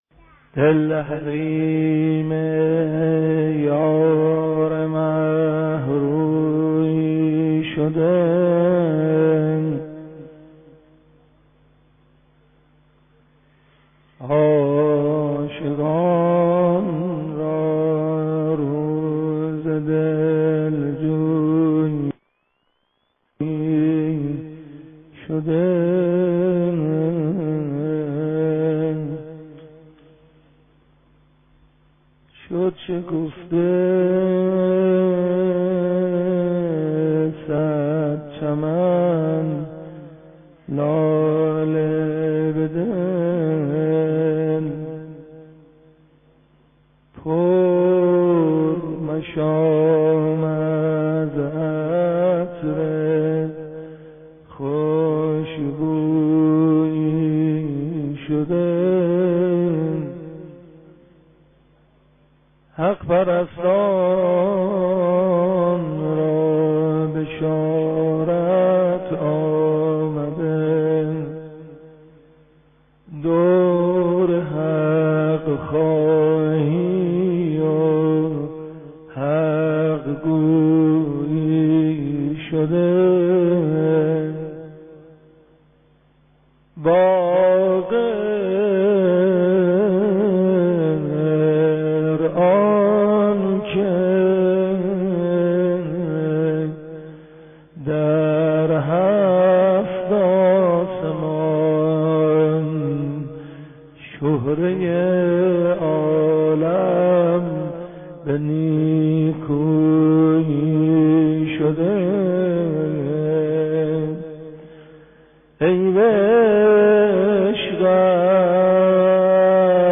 ولادت امام باقر (ع)
مدح